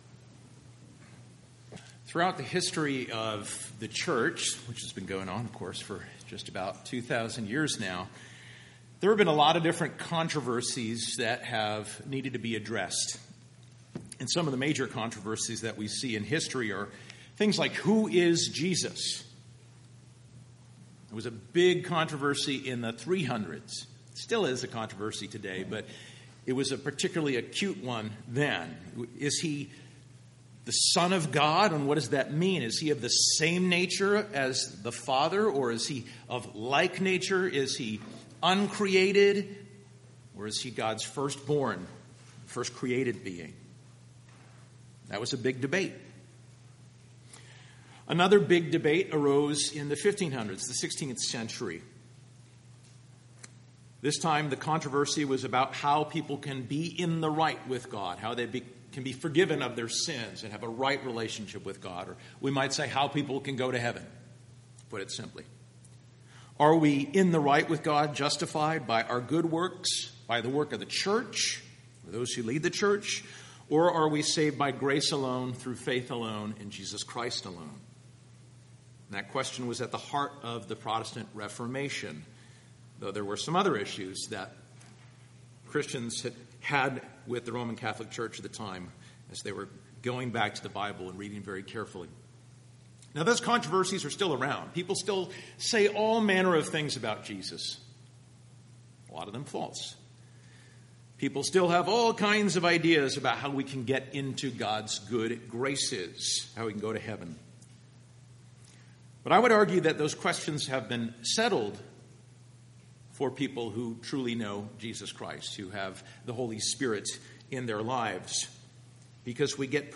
Sermons preached at West Bridgewater Community Church in West Bridgewater, Massachusetts.